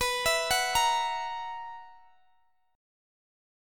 Listen to BM7sus4 strummed